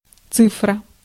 Ääntäminen
IPA : /ˈnjuːməɹəl/ IPA : /ˈnumɝəl/